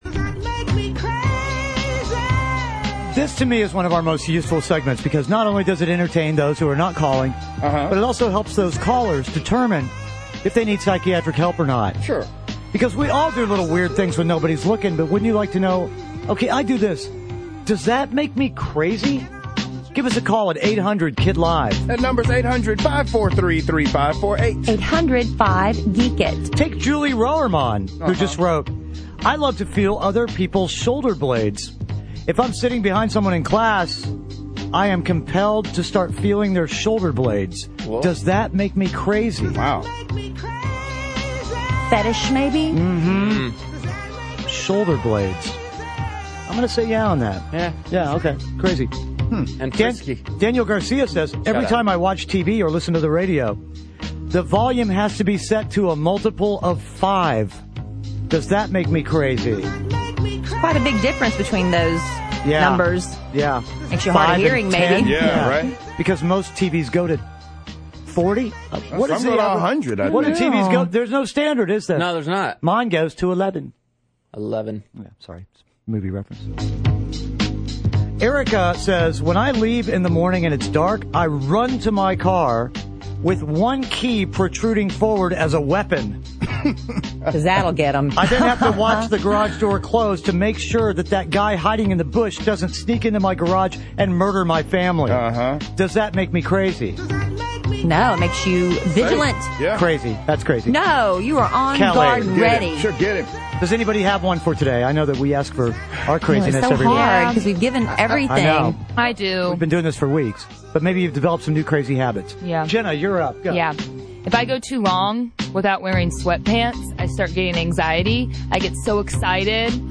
Listeners call in to tell us the crazy things they do! But are they really crazy?